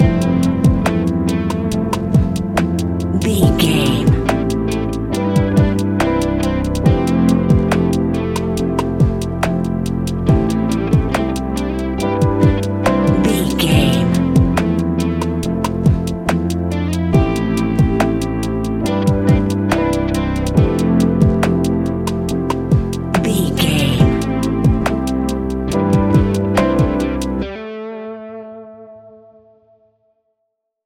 Ionian/Major
chilled
laid back
Lounge
sparse
new age
chilled electronica
ambient
atmospheric